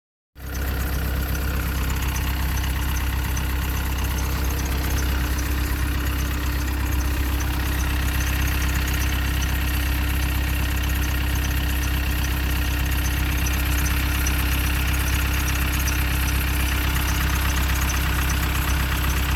Что за звук? - завожу утром холодную вот такой звук - Автомобили и мотоциклы
завожу утром на холодную и вот такой звук, не знаю каждый день или нет.